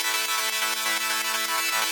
SaS_MovingPad01_125-A.wav